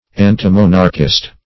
Antimonarchist \An`ti*mon"arch*ist\, n. An enemy to monarchial government.